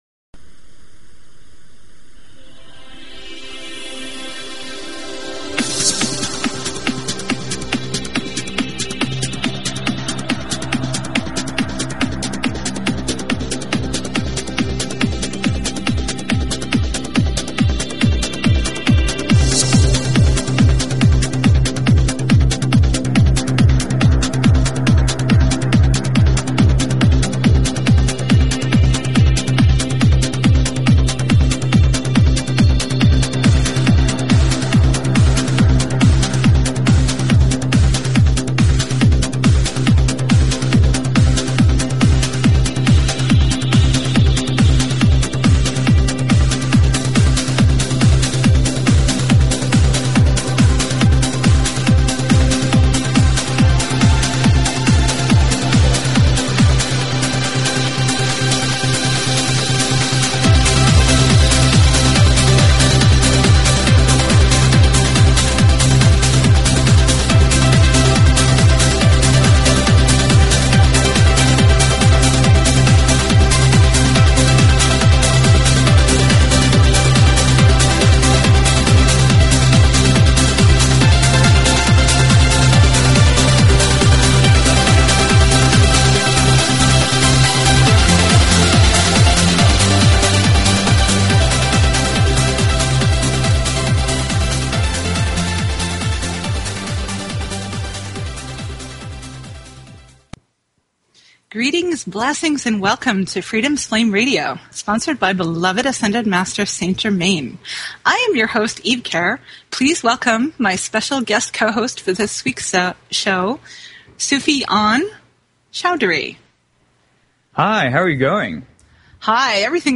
Talk Show Episode, Audio Podcast, Freedoms_Flame_Radio and Courtesy of BBS Radio on , show guests , about , categorized as